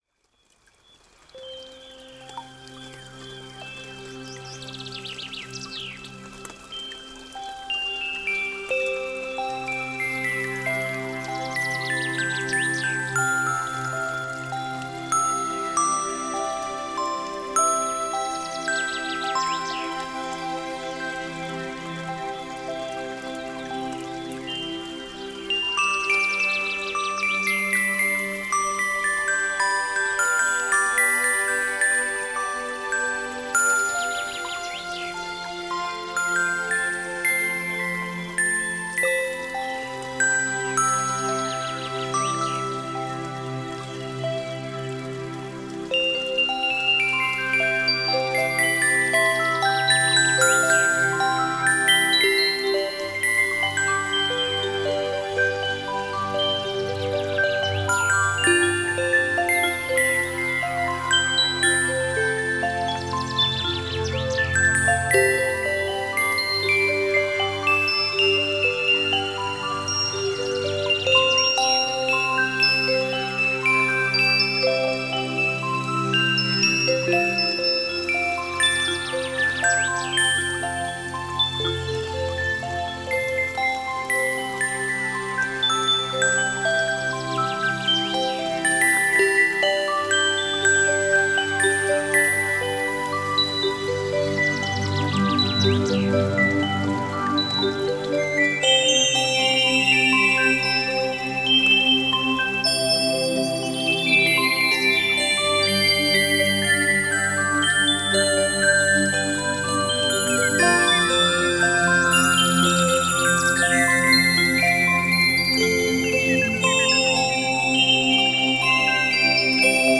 Духовная музыка Музыка для медитации Мистическая музыка